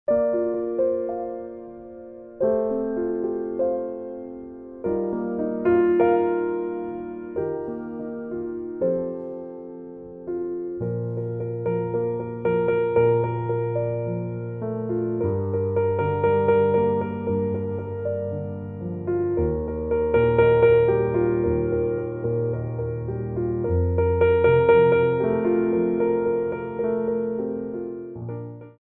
4/4 (8x8)